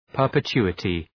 Προφορά
{,pɜ:rpı’tu:ətı}
perpetuity.mp3